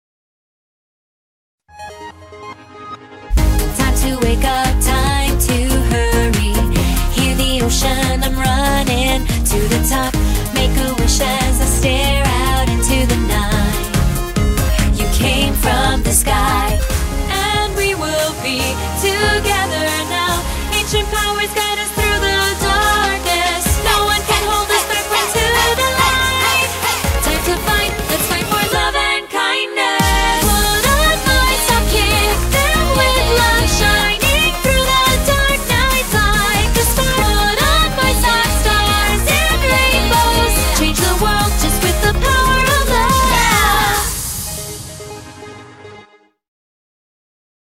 BPM142
Audio QualityPerfect (High Quality)